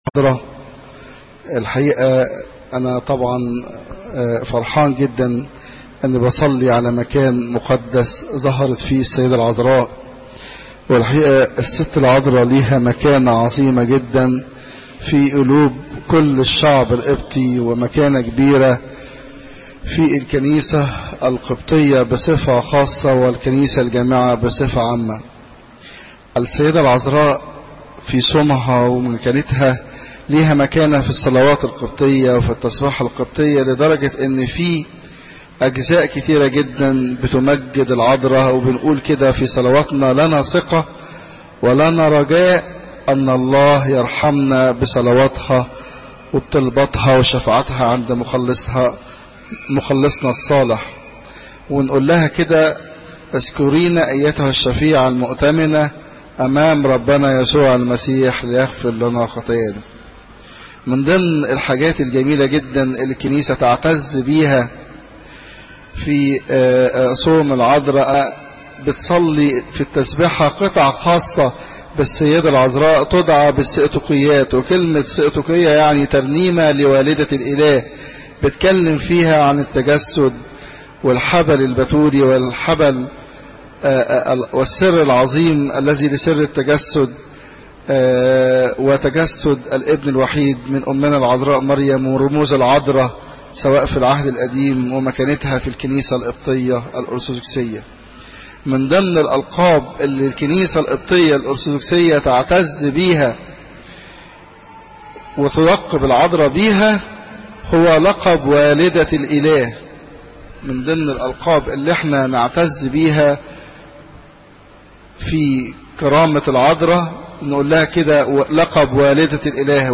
Popup Player تحميل الصوت الانبا مكارى الأحد، 17 أغسطس 2014 15:00 عظات قداسات الكنيسة الزيارات: 1347